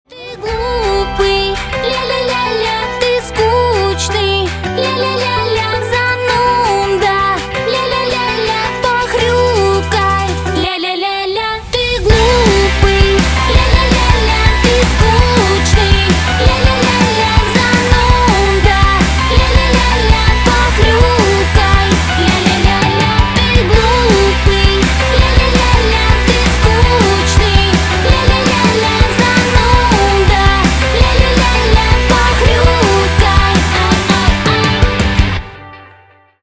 Рок Металл # весёлые